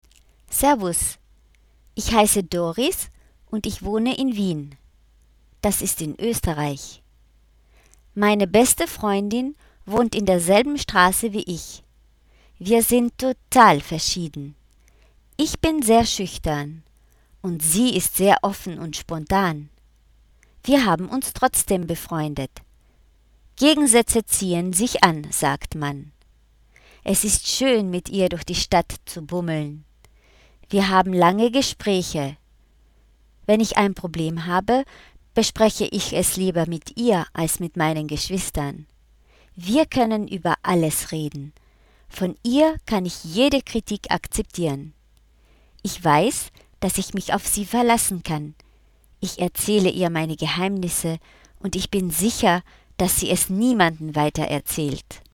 fichiers son de la page du manuel (enregistrés par une collègue d'allemand):